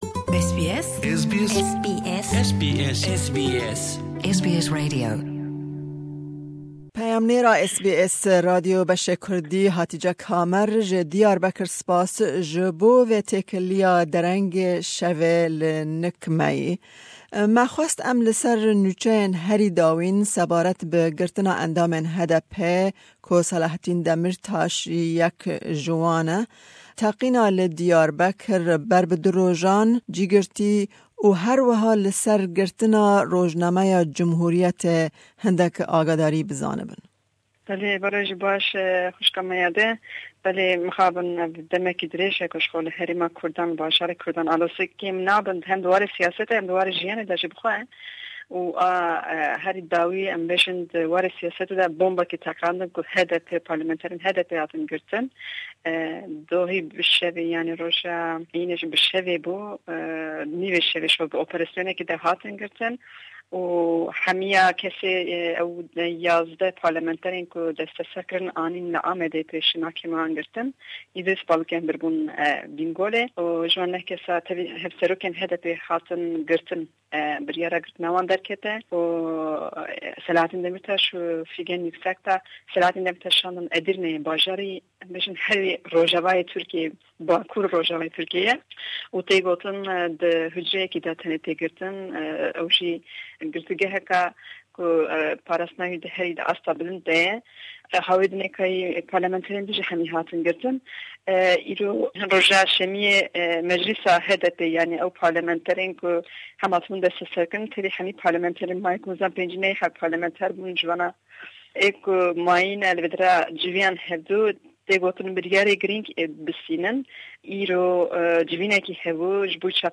Hevpeyvîneke taybet